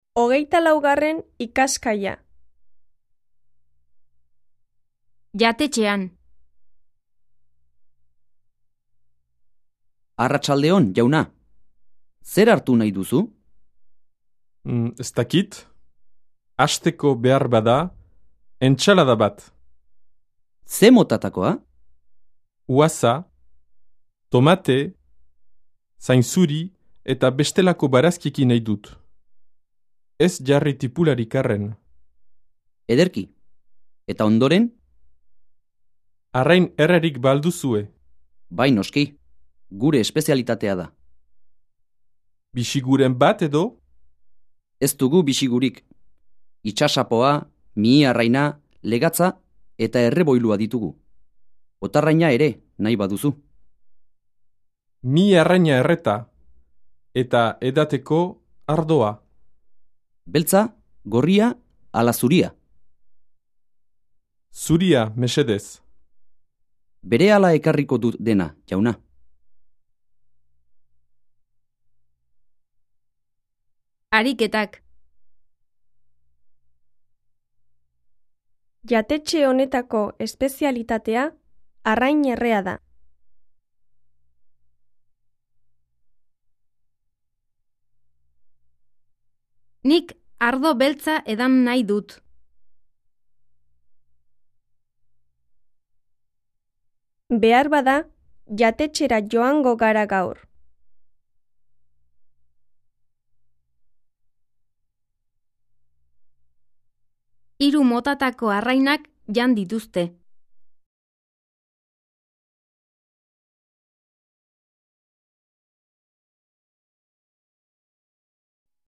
Диалог
2 esstakit 4 u-assa tomate zaynTZuri...